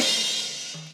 Crashes & Cymbals
CRASHHH.wav